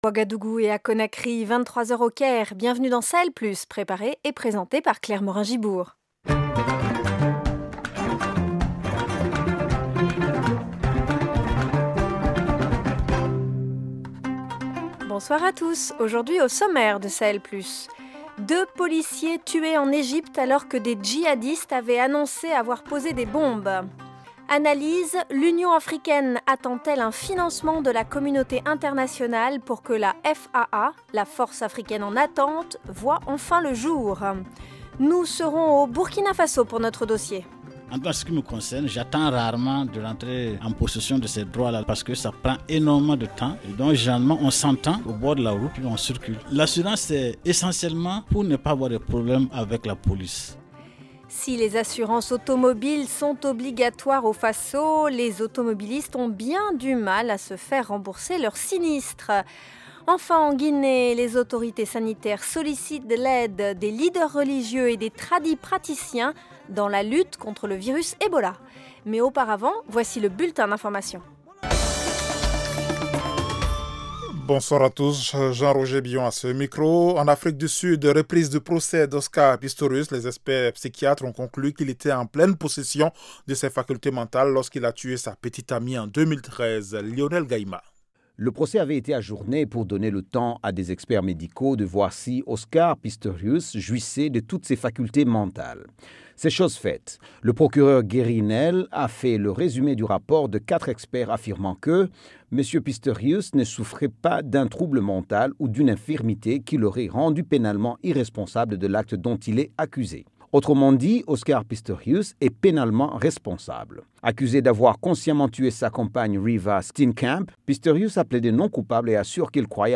Dossier : au Burkina Faso, si les assurances automobiles sont obligatoires, les automobilistes ont bien du mal à se faire rembourser leurs sinistres. Reportage